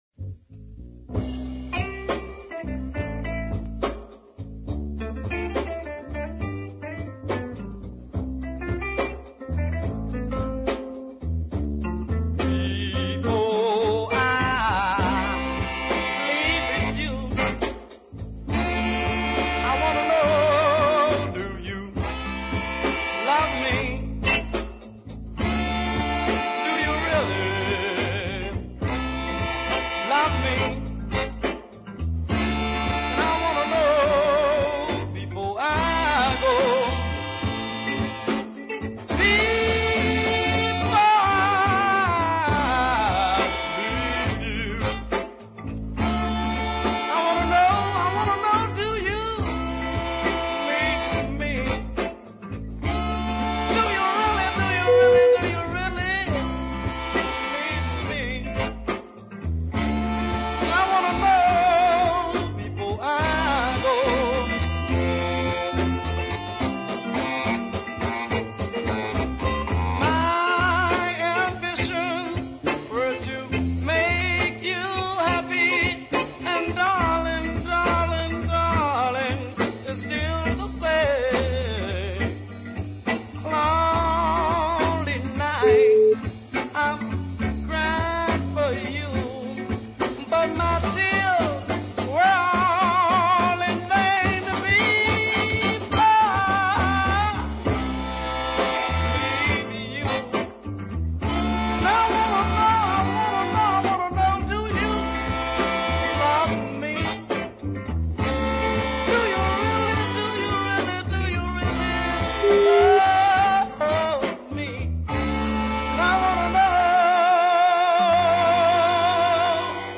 a very fine ballad with big horns